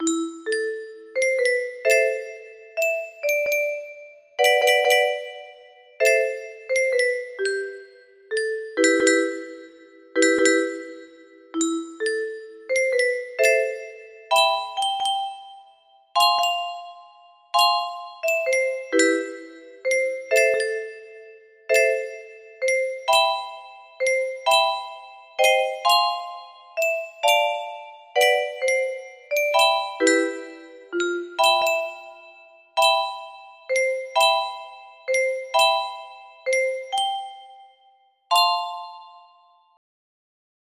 performance assessment music box melody
BPM 65